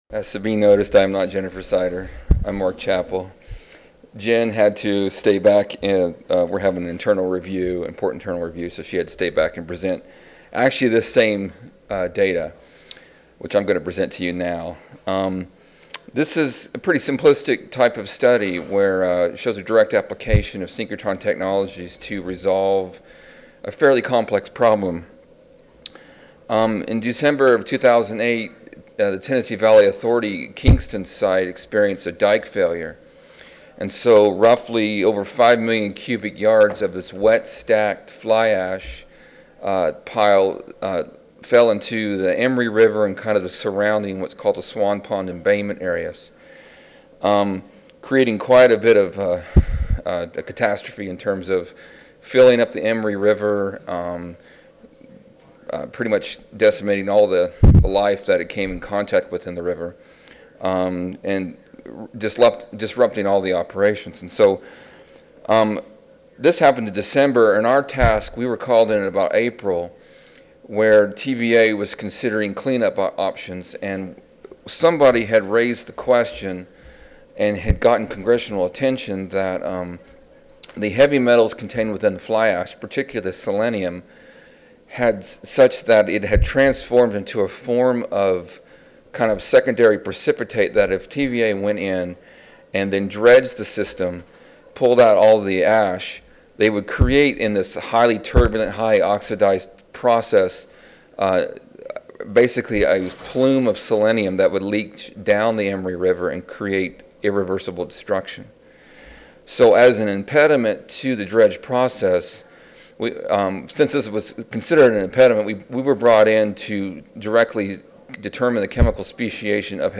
Abstract: Speciation of Selenium and Other Heavy Metals Using X-Ray Absorption Spectroscopy in Coal Fly Ash at the Kingston Fossil Plant Site. (2010 Annual Meeting (Oct. 31 - Nov. 3, 2010))